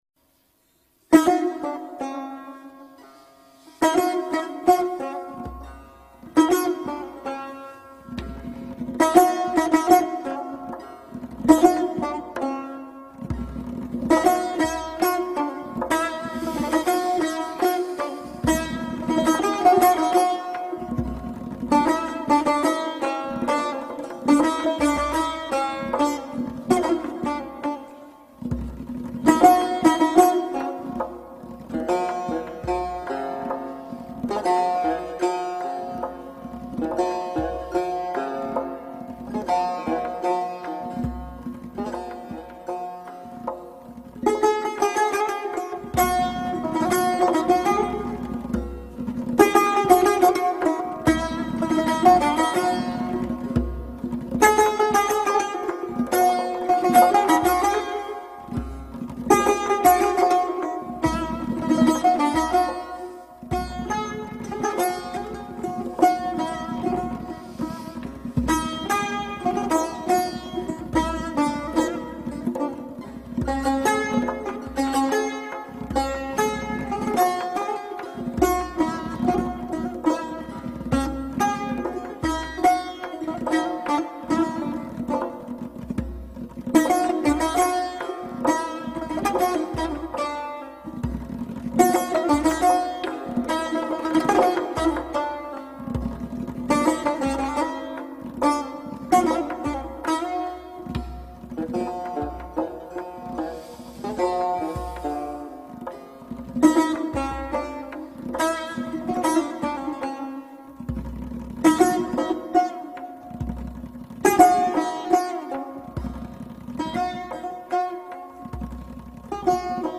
دانلود دکلمه ارغوان با صدای هوشنگ ابتهاج
گوینده :   [هوشنگ ابتهاج]